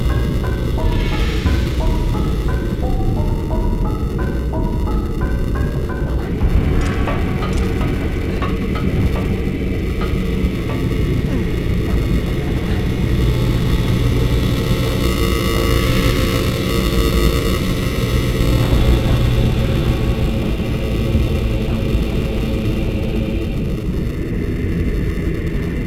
DSP HLE Buzzing in Twilight Princess
Using DSP HLE audio in the 2nd room of the Death Mountain dungeon, if you have the magnetic puller behind link and the camera, the game makes a buzzing/hissing sound that sounds like audio interference.
Also, I noticed the entire dungeon has a much quieter buzz throughout that is only present on HLE.
DSP HLE Example
The issue is related to surround reverb.